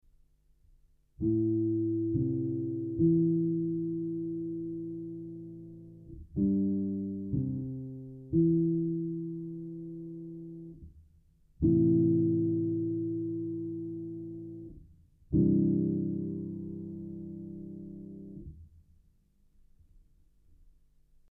Geophone is an omnidirectional contact microphone.
Piano